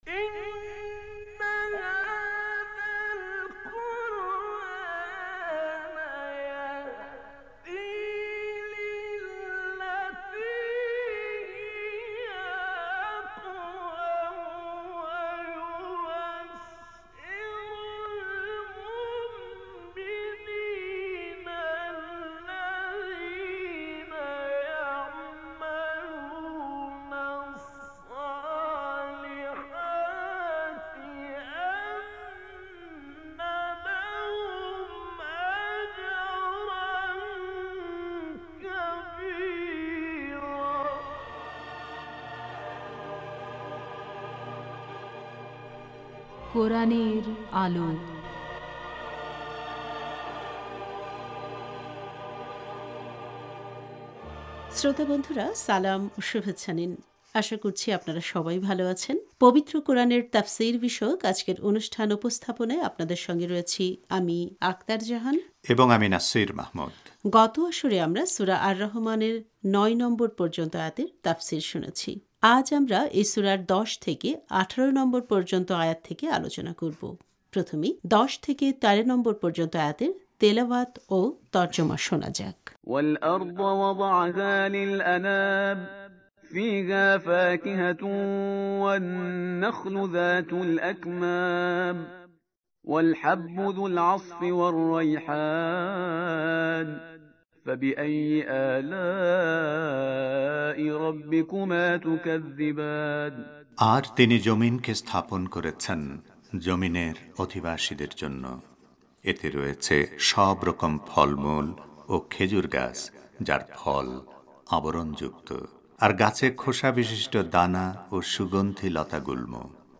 আজ আমরা এই সূরার ১০ থেকে ১৮ নম্বর পর্যন্ত আয়াত নিয়ে আলোচনা করব। প্রথমেই ১০ থেকে ১৩ নম্বর পর্যন্ত আয়াতের তেলাওয়াত ও তর্জমা শোনা যাক: